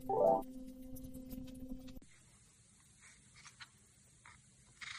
Free SFX sound effect: Saw Cutting.
Saw Cutting
# saw # cutting # wood # tool About this sound Saw Cutting is a free sfx sound effect available for download in MP3 format.
401_saw_cutting.mp3